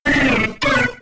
Cri de Piafabec K.O. dans Pokémon X et Y.